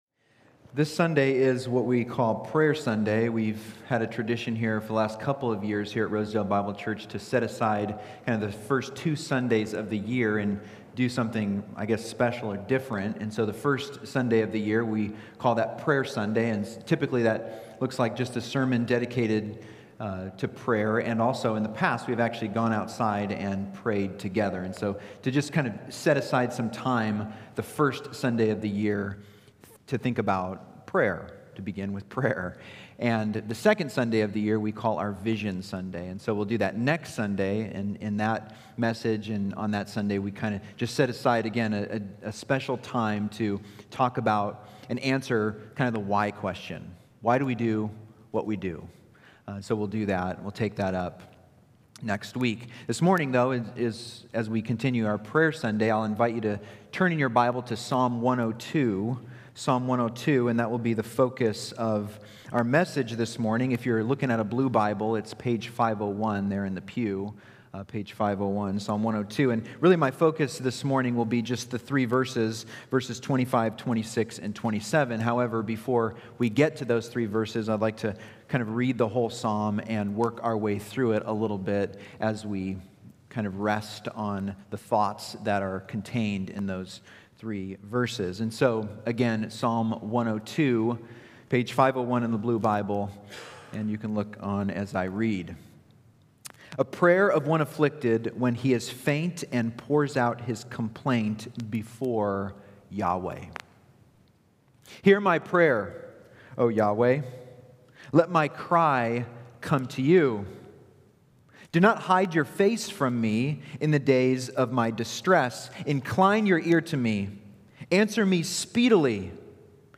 Sermons by Rosedale Bible Church